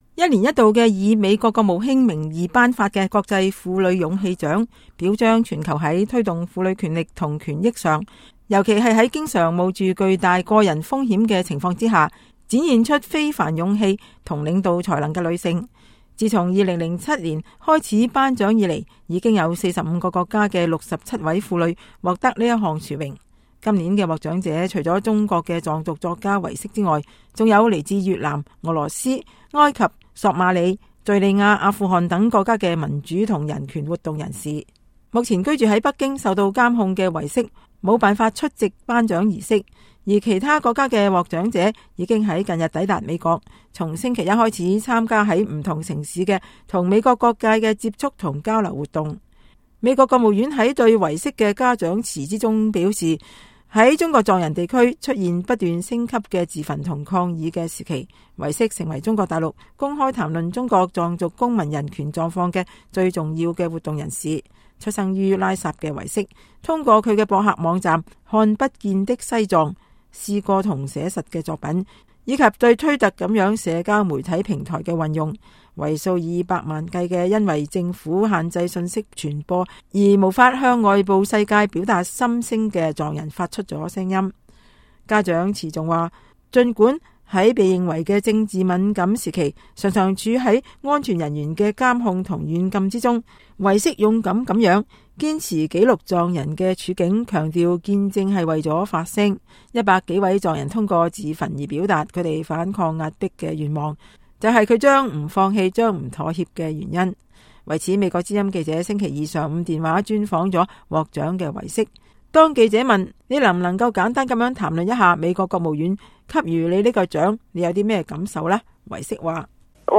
專訪：藏族作家唯色榮獲美國國際婦女勇氣獎